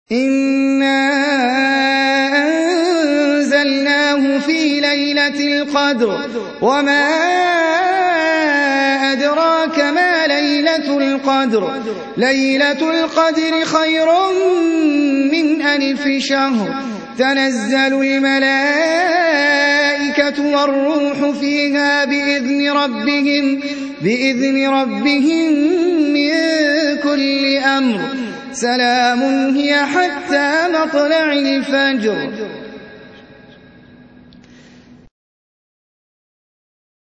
Surah Sequence تتابع السورة Download Surah حمّل السورة Reciting Murattalah Audio for 97. Surah Al-Qadr سورة القدر N.B *Surah Includes Al-Basmalah Reciters Sequents تتابع التلاوات Reciters Repeats تكرار التلاوات